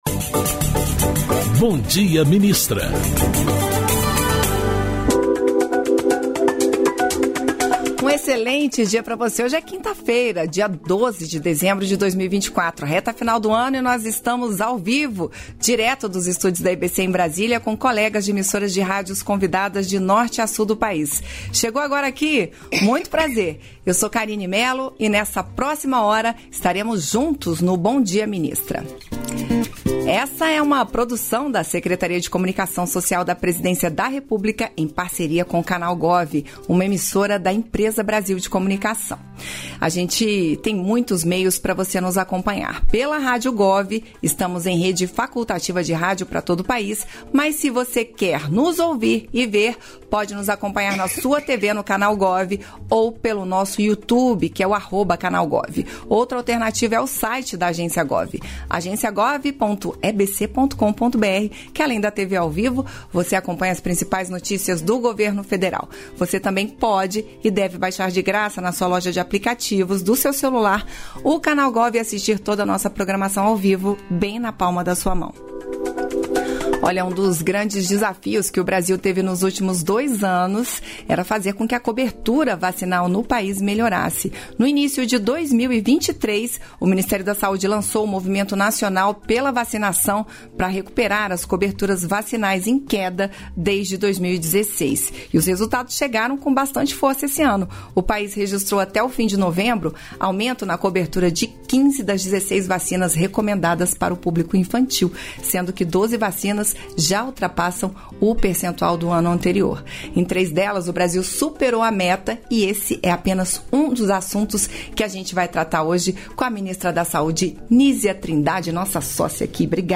Íntegra da participação da ministra da Saúde, Nísia Trindade, no programa "Bom Dia, Ministra" desta quinta-feira (12), nos estúdios da EBC, em Brasília.